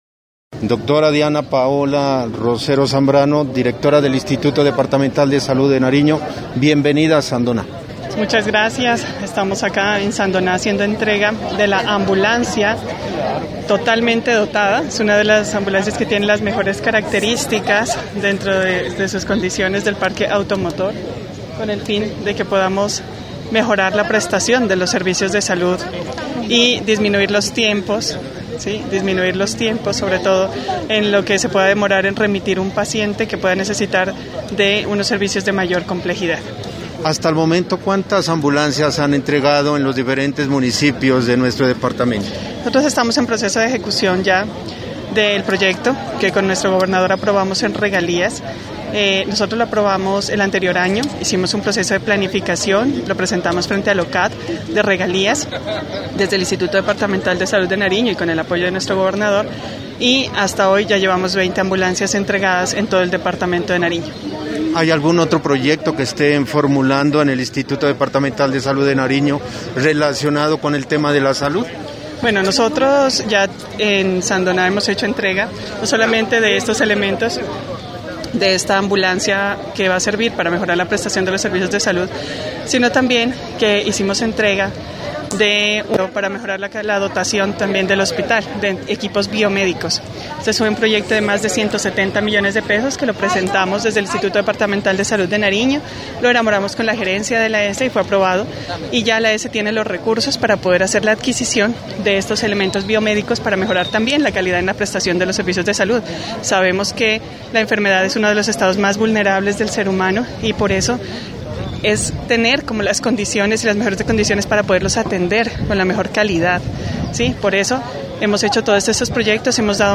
Entrevista con la directora del IDSN Diana Paola Rosero: